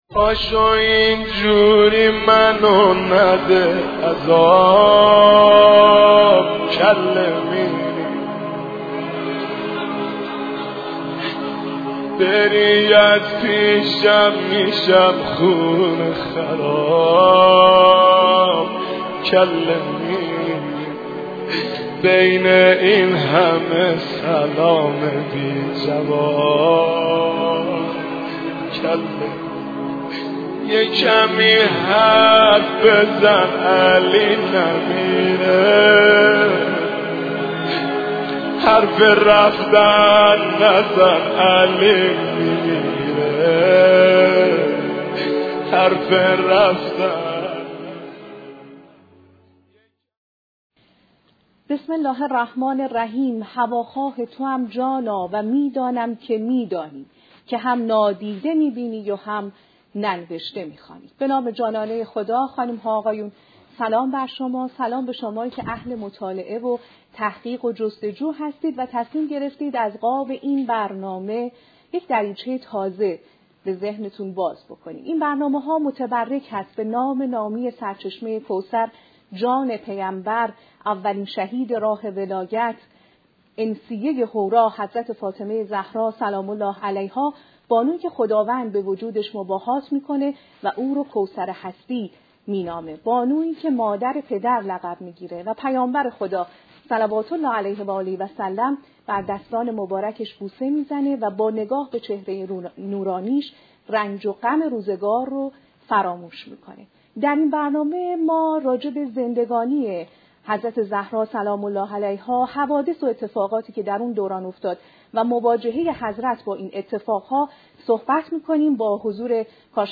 هدف برنامه، بازکردن دریچه‌ای جدید برای شناخت زندگی آن حضرت از طریق مطالعه و تحقیق عنوان می‌گردد. 2- موضوع برنامه و معرفی مهمان: محور اصلی برنامه،بررسی زندگانی حضرت زهرا(س)، حوادث دوران حیات ایشان و نحوه مواجهه حضرت با این اتفاقات است. این بررسی با حضور یک کارشناس و پژوهشگر حوزه و دانشگاه انجام می‌شود. 3- چارچوب تحلیل تاریخی: کارشناس برنامه تأکید می‌کند که برای تحلیل دقیق وقایع پس از رحلت پیامبر(ص)،باید ریشه‌ها را در حوادث پیش از آن، به‌ویژه واقعه غدیر و مسئله جانشینی امام علی(ع) جستجو کرد.